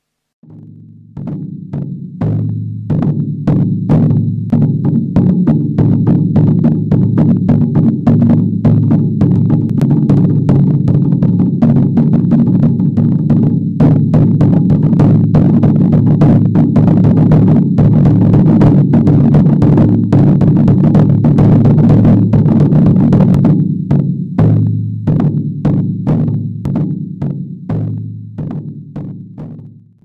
香肠热卖
描述：蛋锅里煮香肠的嗞嗞声
标签： 厨房 早餐 烹调香肠 香肠铁板 嘶嘶声 香肠
声道立体声